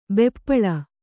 ♪ beppaḷa